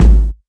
Kick Drum Sound G# Key 238.wav
Royality free kick single shot tuned to the G# note. Loudest frequency: 148Hz
kick-drum-sound-g-sharp-key-238-6aR.ogg